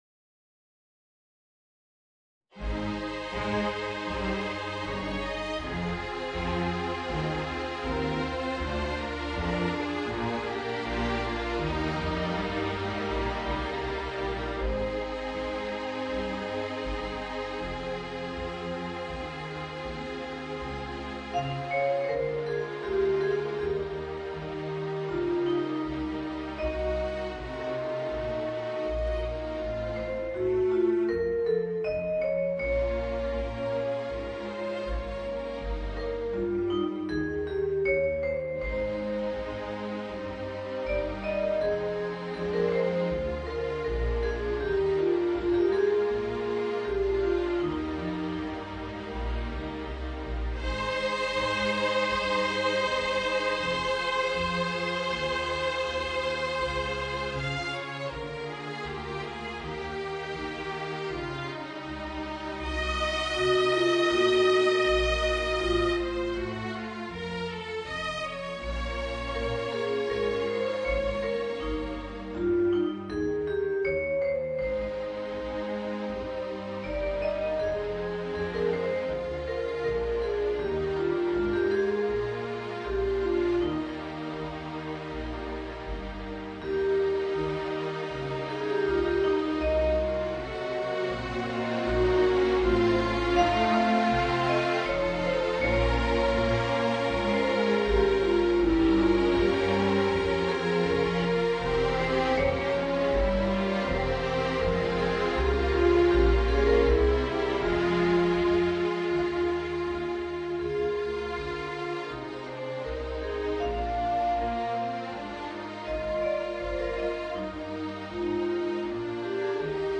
Voicing: Vibraphone and String Orchestra